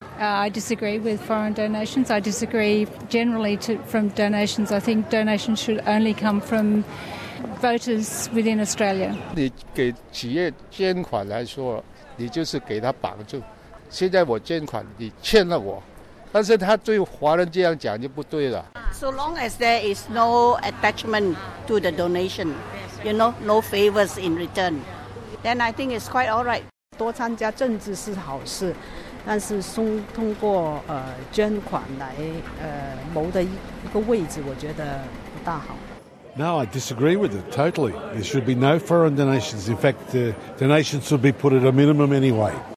政 治捐款是否应该改革？禁止海外政治献金的建议，你同意吗？ 就这些问题，SBS电台记者在悉尼chatswood 街头采访了多位市民： 请听部分采访录音。